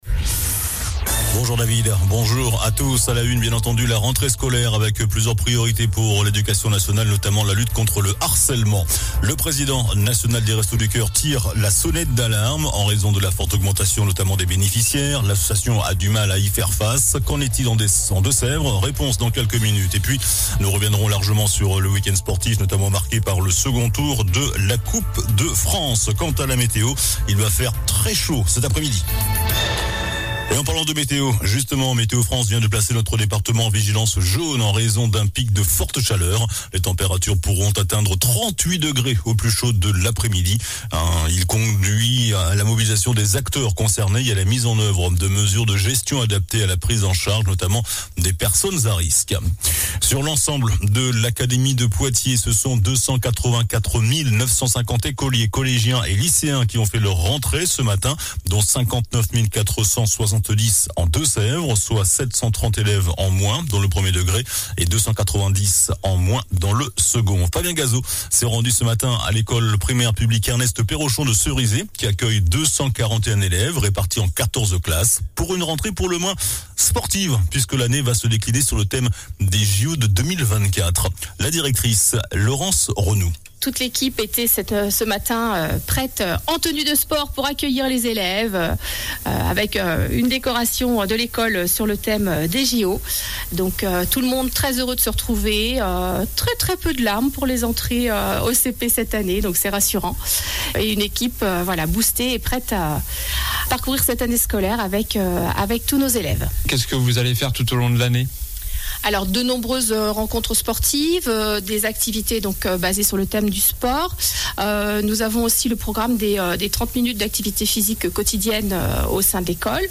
JOURNAL DU LUNDI 04 SEPTEMBRE ( MIDI )